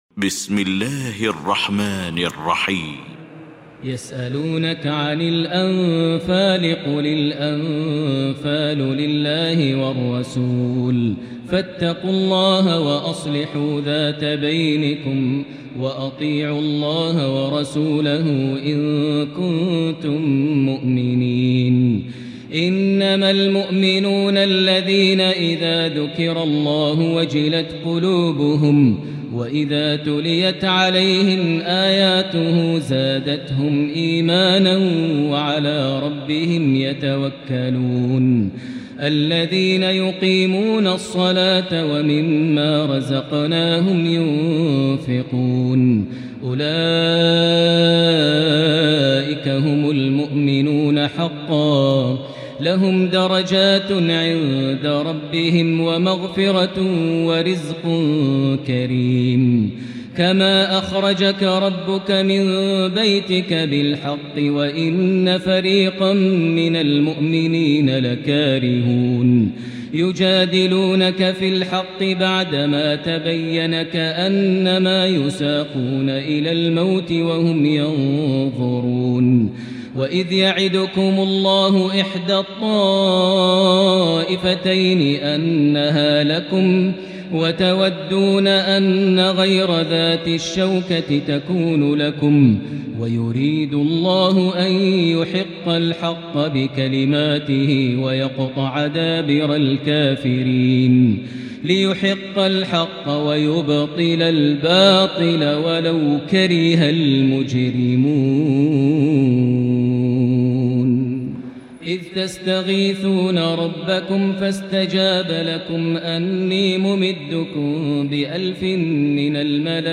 المكان: المسجد الحرام الشيخ: معالي الشيخ أ.د. بندر بليلة معالي الشيخ أ.د. بندر بليلة فضيلة الشيخ ماهر المعيقلي الأنفال